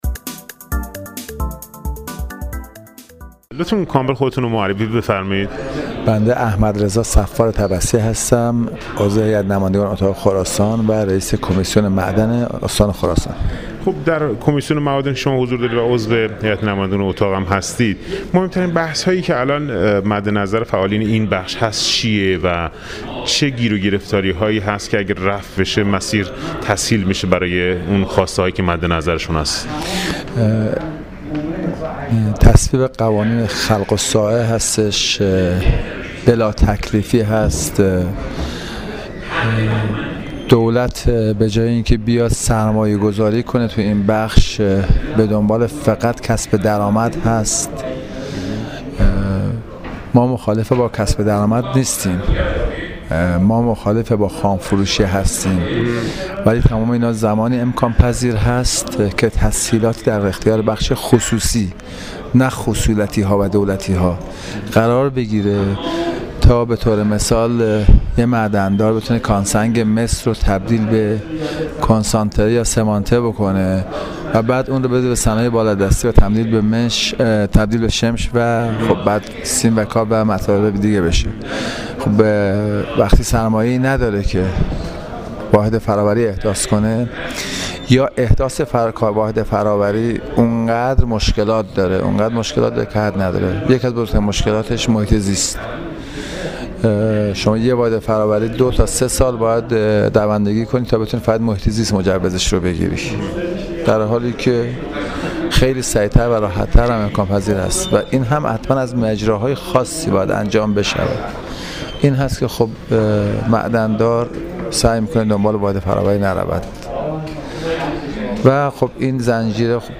[گفت‌وگوی رادیویی]